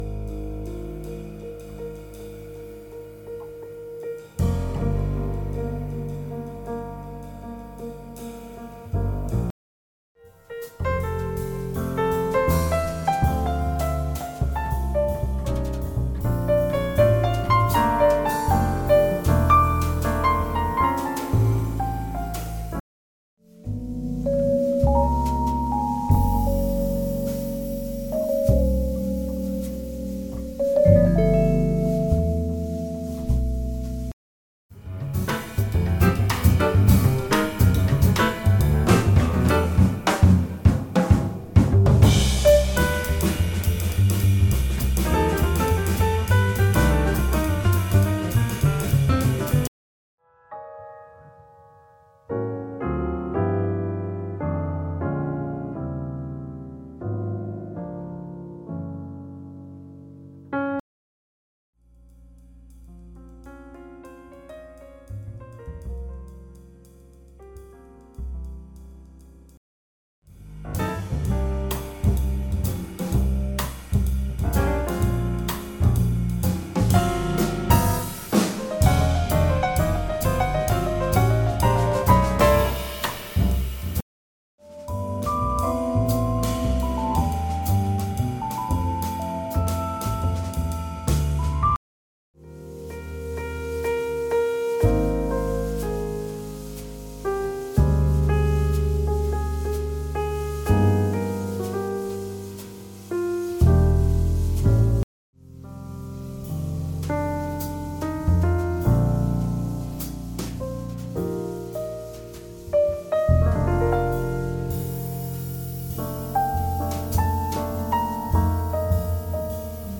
jazzalbum